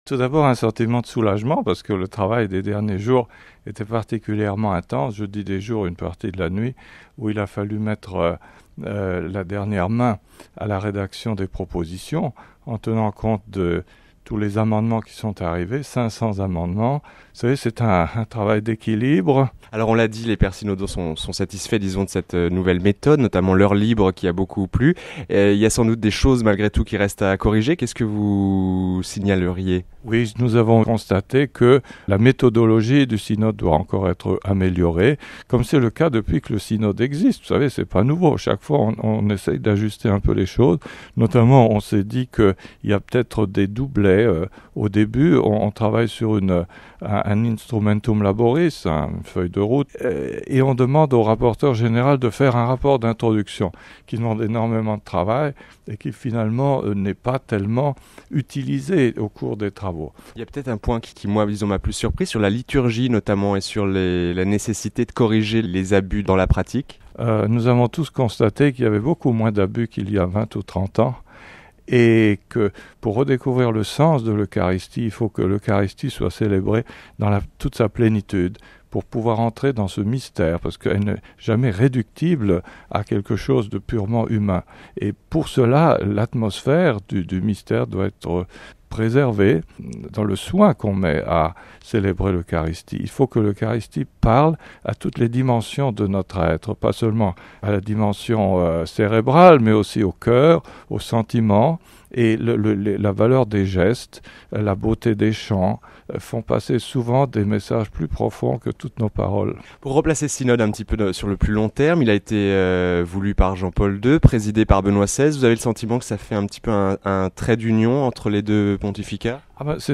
(RV - lundi 24 octobre) L’invité du dossier de la rédaction est Mgr Roland Minnerath, archevêque de Dijon en France. En sa qualité de secrétaire spécial du synode des évêques, il a eu la lourde tache de sélectionner et présenter les propositions qui permettront au Pape de promulguer son exhortation post-synodale.